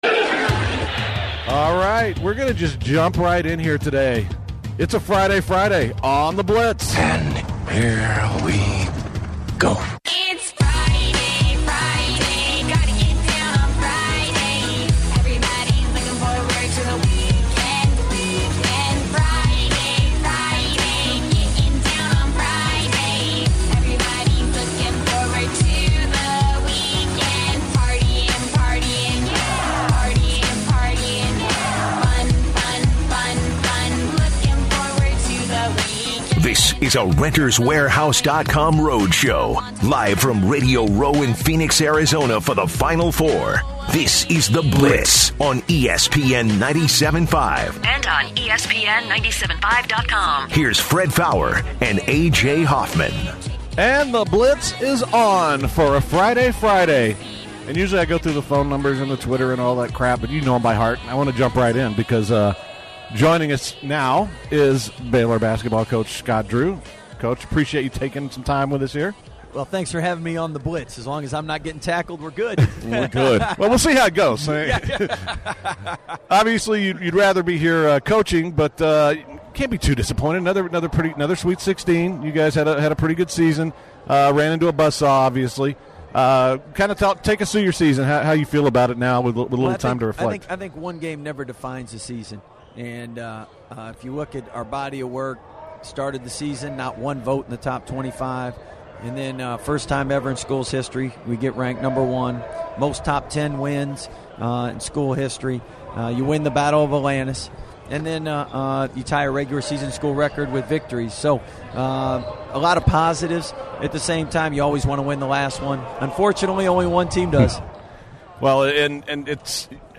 The Blitz continues there live broadcast from the Final Four in Arizona.